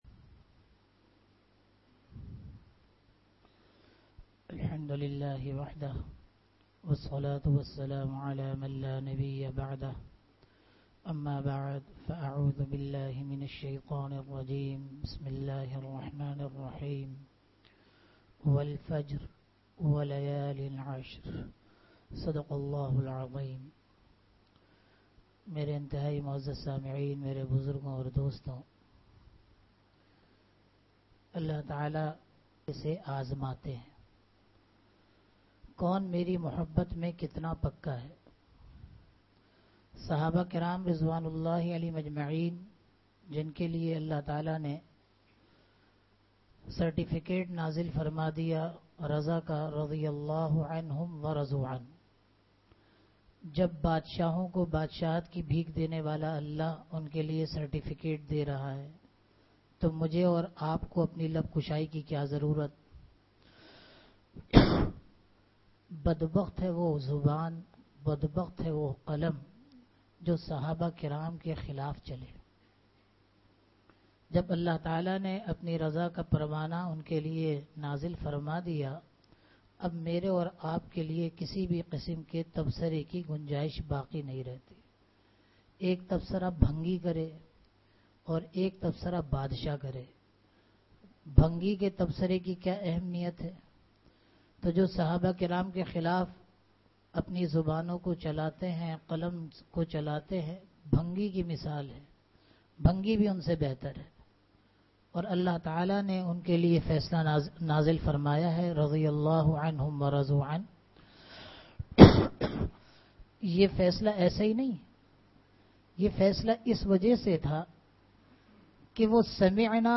jummah byan
Bayanat
Bayanat Bayanat (Jumma Aur Itwar) jummah byan 30th May 2025 Category Bayanat Sub-Category Bayanat (Jumma Aur Itwar) Date 30th May 2025 Size 6.66 MB Tags: Download Source 1 Download Source 2 Share on WhatsApp